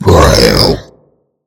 TYPHLOSION.mp3